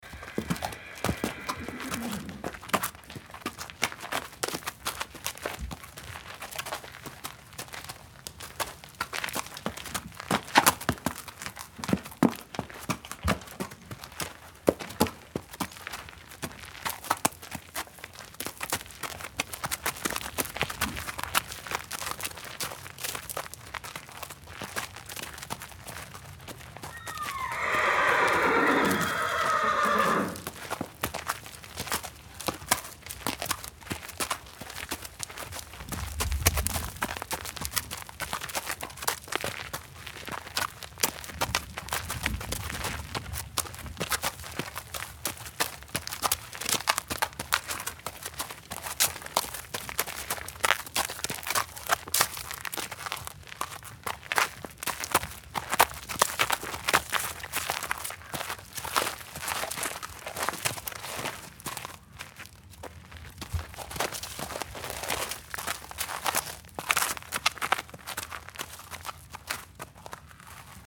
Медленные шаги лошади на беговой дорожке